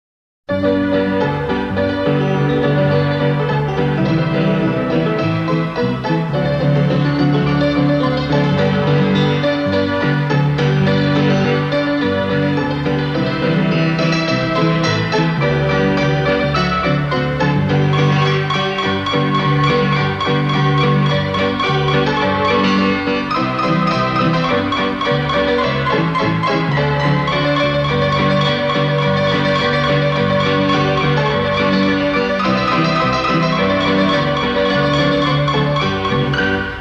MIDGET COINOLA